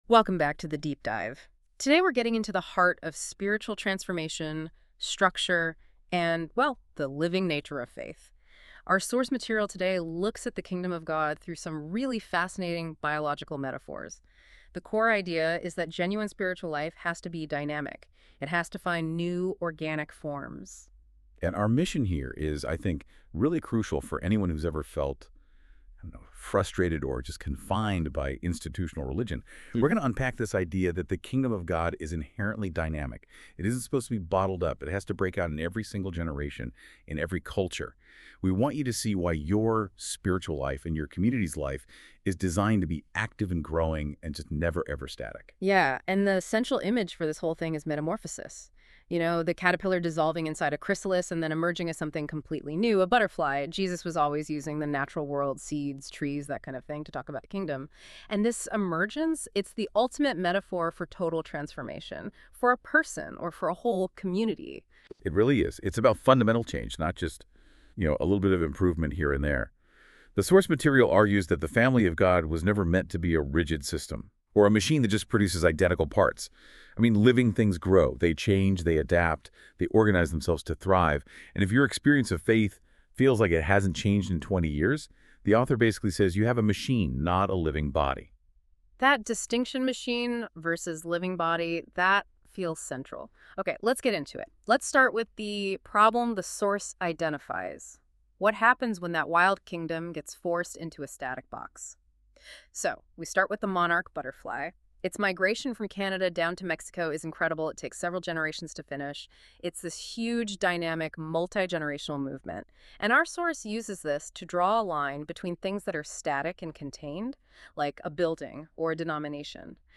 Deep Dive AI dialogue (15 minutes) about some of the content in From Cocoon To New Wings by Ross Rains.